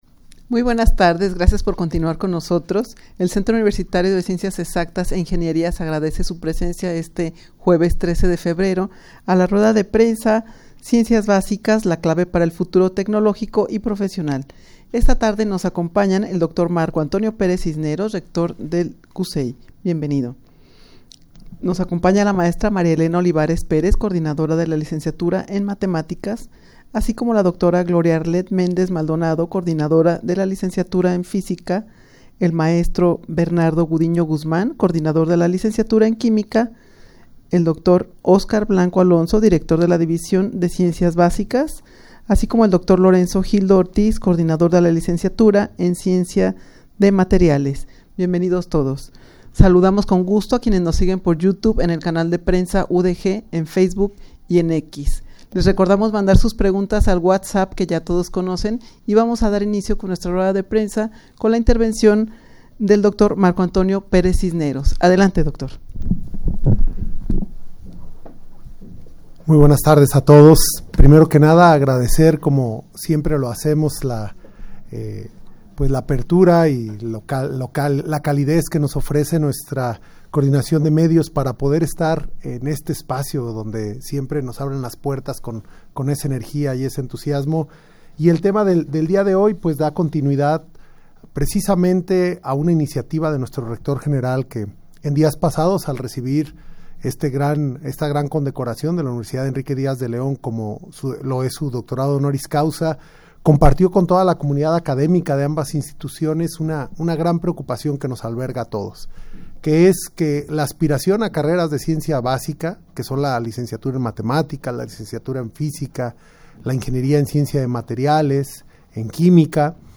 Audio de la Rueda de Prensa
rueda-de-prensa-ciencias-basicas-la-clave-para-el-futuro-tecnologico-y-profesional.mp3